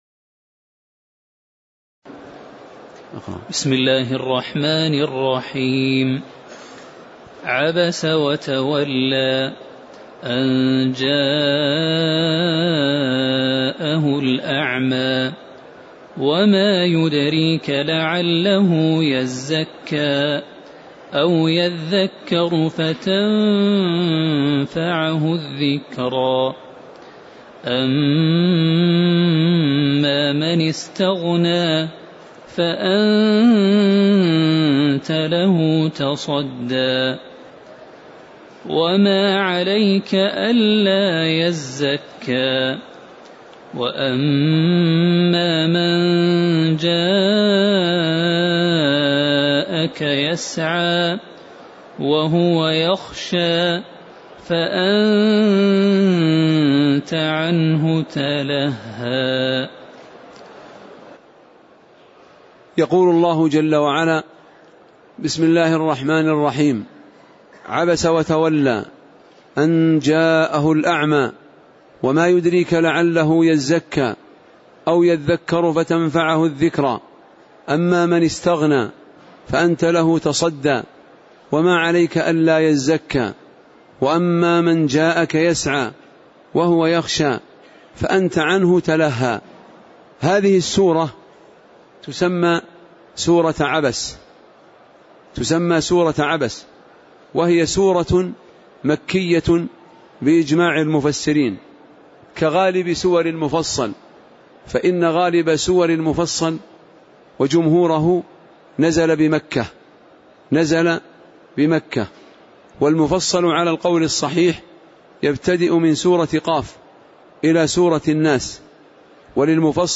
تاريخ النشر ١٣ شوال ١٤٣٨ هـ المكان: المسجد النبوي الشيخ